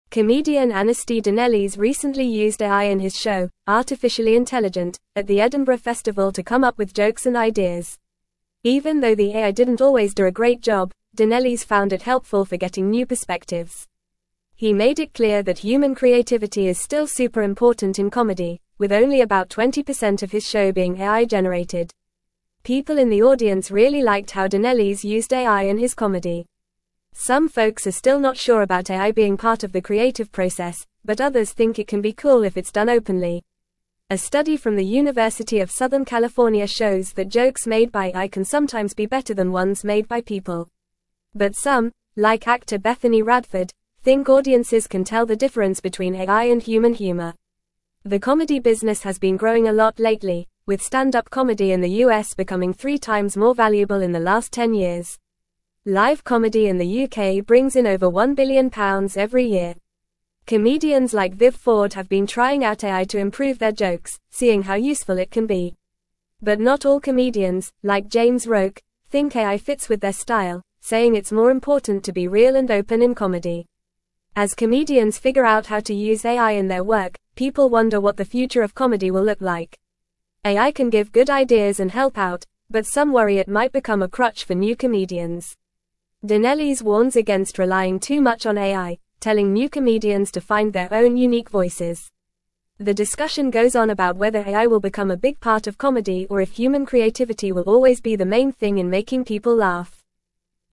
Fast
English-Newsroom-Upper-Intermediate-FAST-Reading-Comedians-Embrace-AI-for-Joke-Writing-and-Shows.mp3